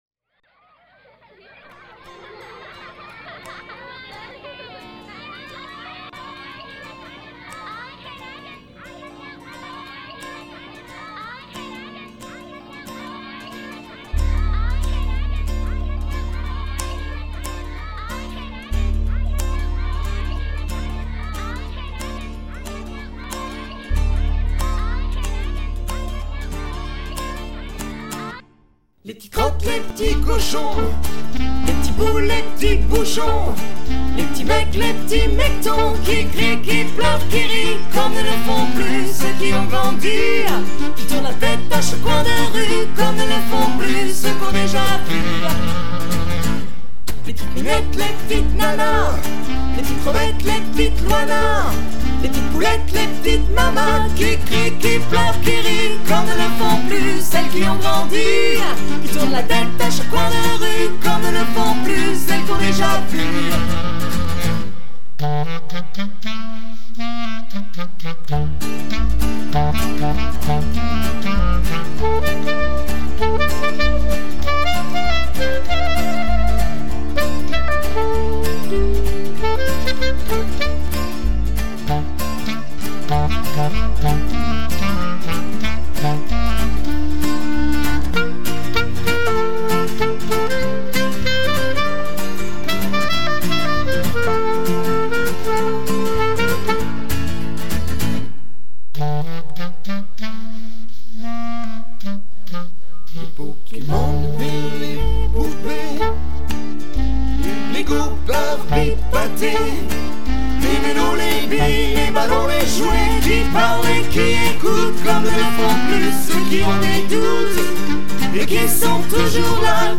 acoustik dynamiiik
chanson française décalée 2004